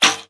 archer_attack2.wav